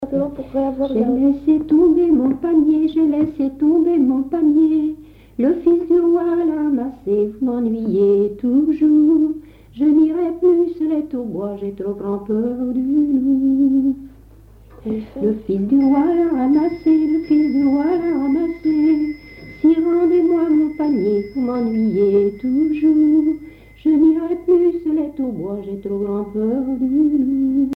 Localisation Mont-Dol (Plus d'informations sur Wikipedia)
Genre laisse
Catégorie Pièce musicale inédite